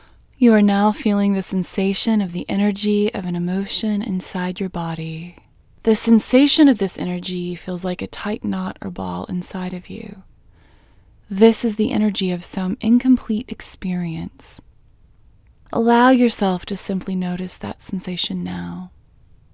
IN Technique First Way – Female English 2